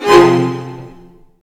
Index of /90_sSampleCDs/Zero-G - Total Drum Bass/Instruments - 2/track57 (Strings)